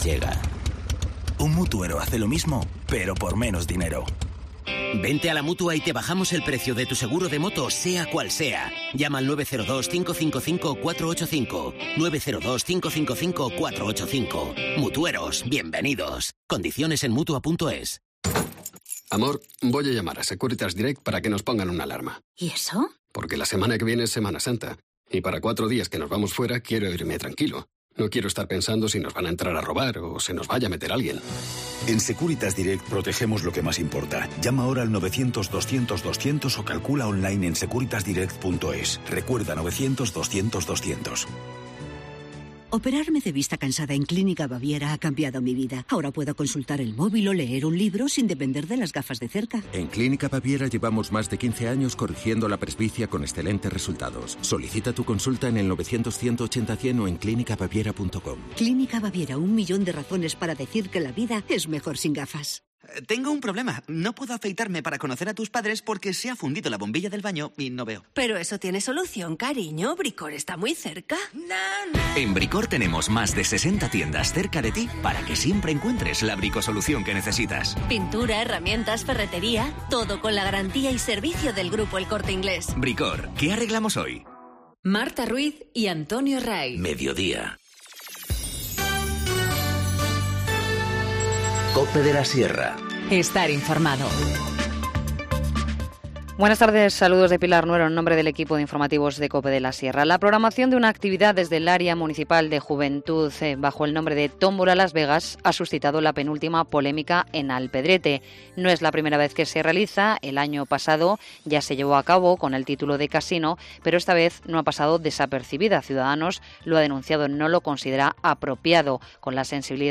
Informativo Mediodía 10 abril 14:20h